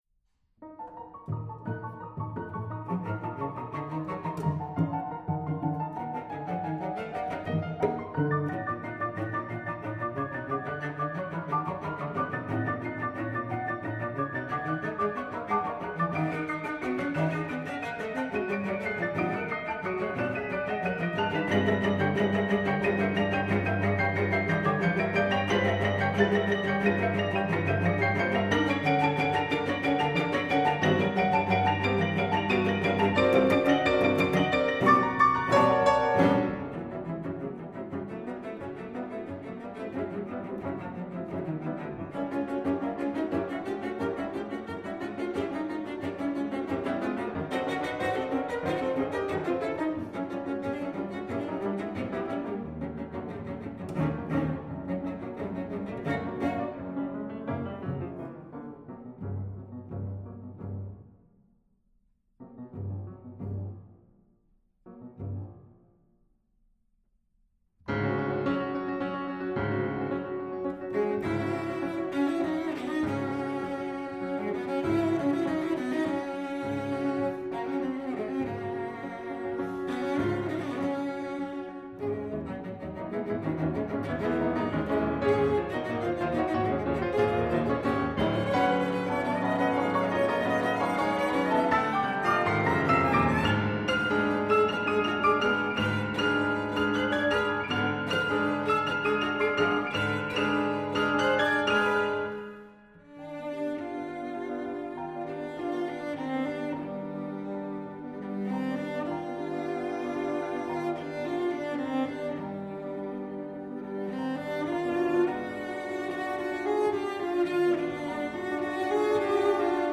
'cello and piano
highly energetic